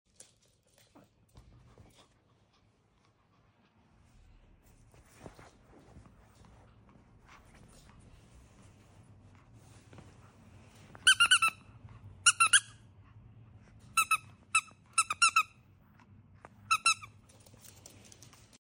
I heared a toy sound sound effects free download